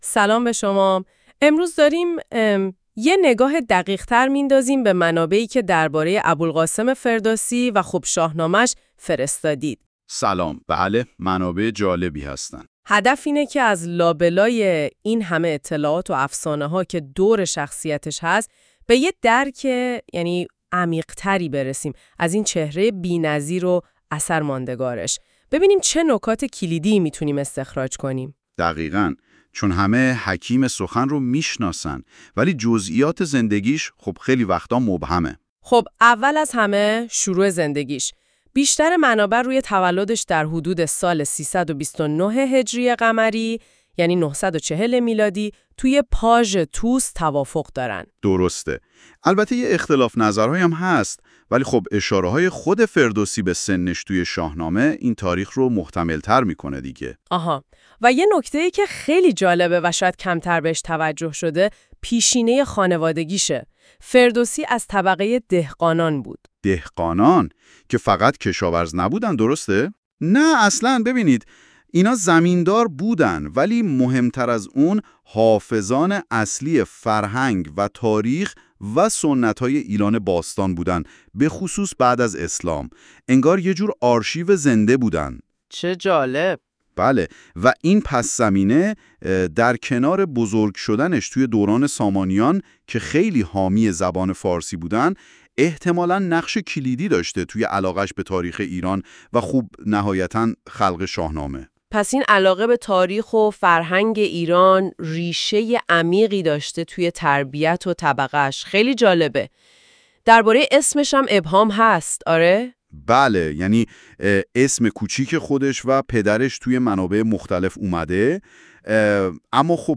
این فایل صوتی با استفاده از هوش مصنوعی تولید شده است تا تجربه‌ای بهتر و کاربرپسندتر برای شما فراهم شود.